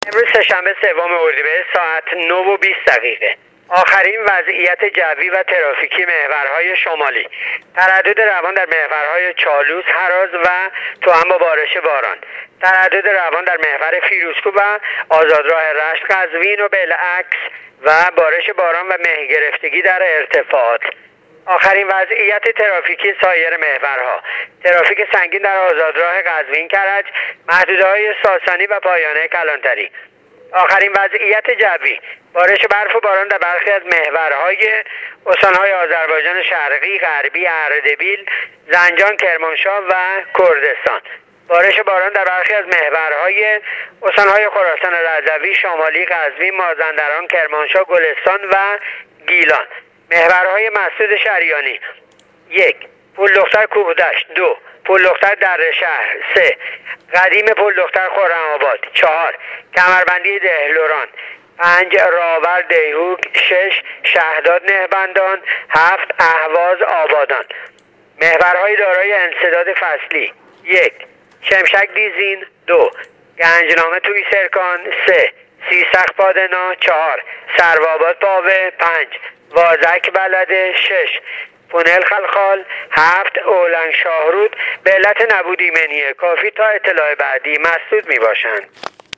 گزارش رادیو اینترنتی وزارت راه و شهرسازی از آخرین وضعیت‌ ترافیکی راه‌های کشور تا ساعت ۹:۲۰ سوم اردیبهشت/ تردد عادی و روان در محورهای شمالی کشور/ترافیک سنگین در محورهای قزوین - کرج